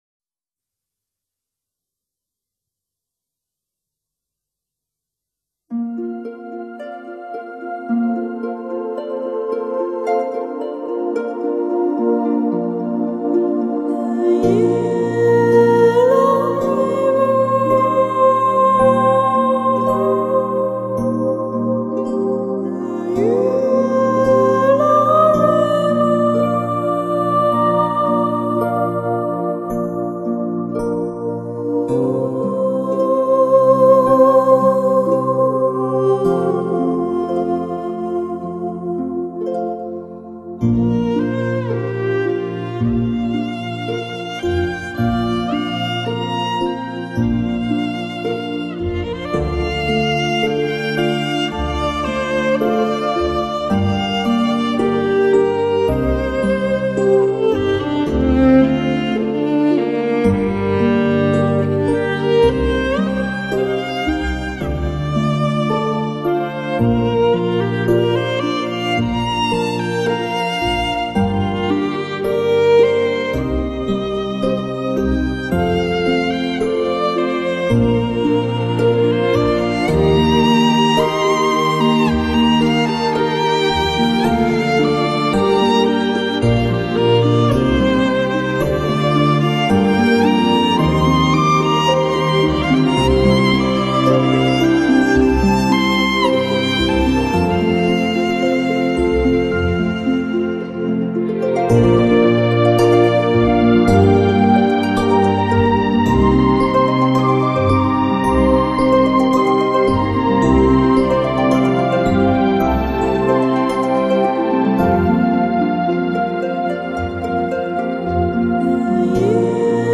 小提琴演奏
熟悉的旋律，由小提琴重新演绎，尽顾音乐的张力。